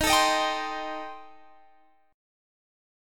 Eb6b5 chord